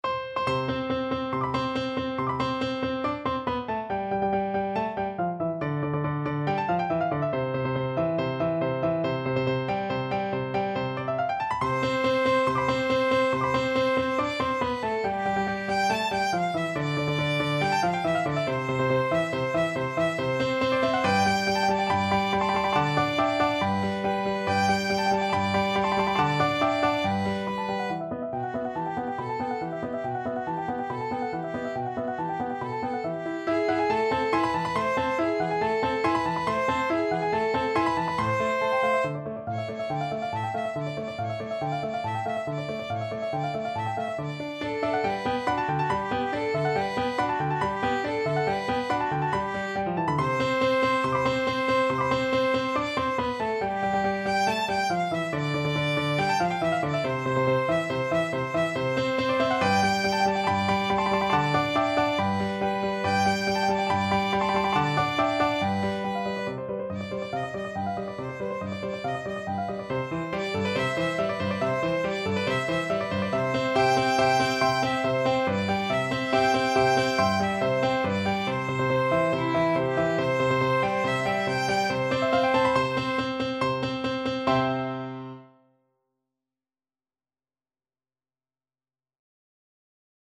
Classical Mozart, Wolfgang Amadeus Bassa Selim lebe lange from Die Entfuhrung aus dem Serail, K.384 Violin version
C major (Sounding Pitch) (View more C major Music for Violin )
~ = 140 Allegro vivace (View more music marked Allegro)
2/4 (View more 2/4 Music)
Classical (View more Classical Violin Music)